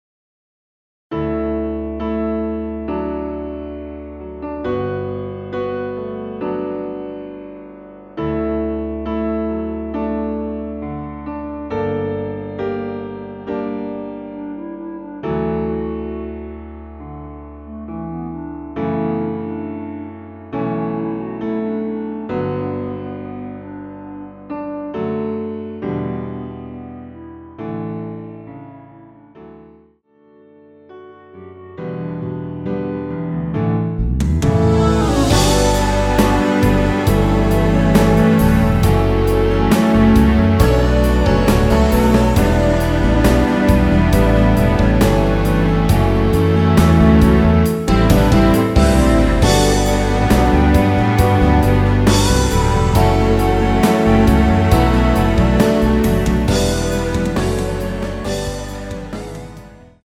원키에서(-4)내린 멜로디 포함된 MR입니다.
◈ 곡명 옆 (-1)은 반음 내림, (+1)은 반음 올림 입니다.
멜로디 MR이라고 합니다.
앞부분30초, 뒷부분30초씩 편집해서 올려 드리고 있습니다.